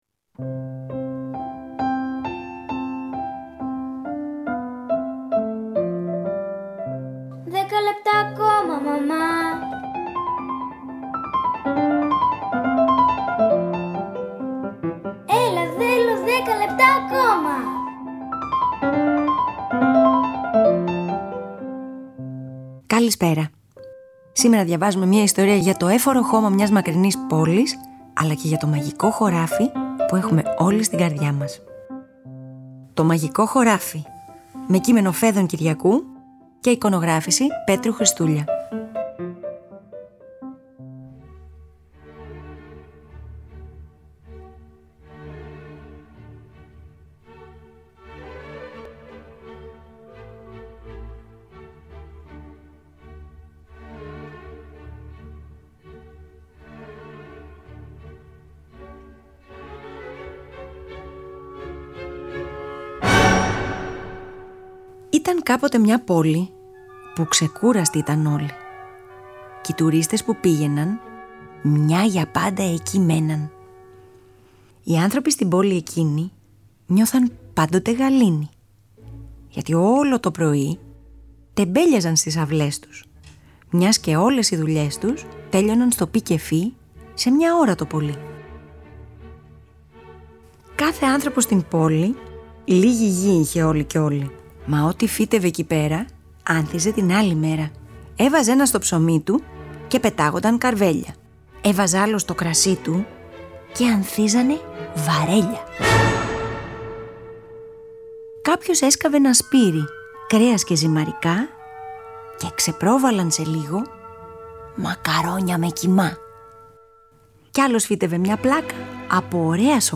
Διαβάζουμε μια ιστορία για το εύφορο χώμα μιας μακρινής πόλης, αλλά και για το μαγικό χωράφι που έχουμε όλοι στην καρδιά μας.